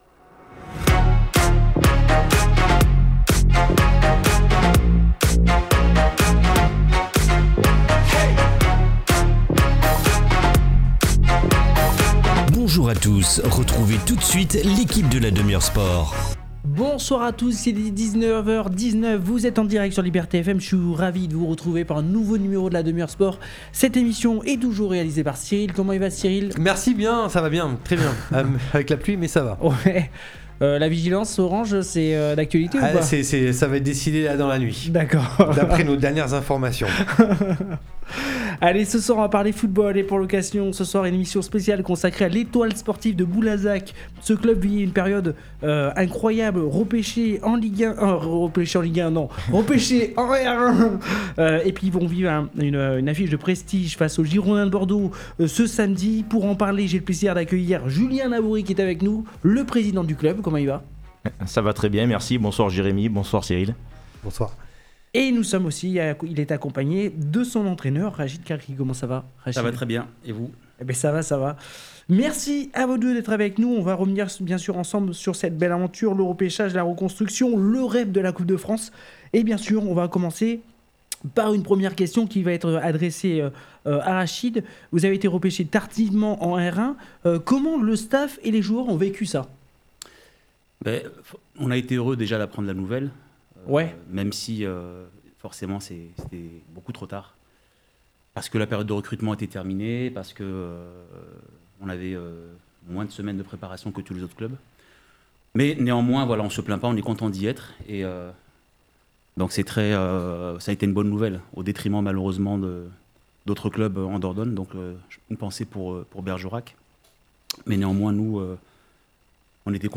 Dans La Demi-Heure Sport sur Liberté FM 96.1, nous recevons deux invités exceptionnels :